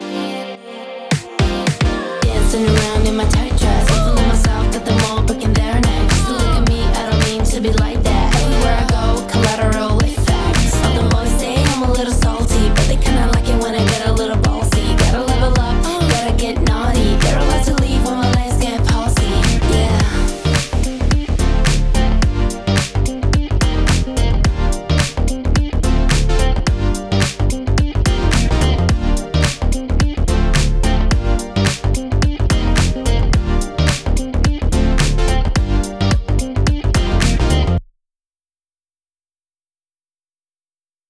今回はシンプルな構成のデモ曲（ドラム、ベース、ギター、ボーカル）を使って、各機能を確認していきます。
【まとめ】Neutron 5の各モジュール適用前後の比較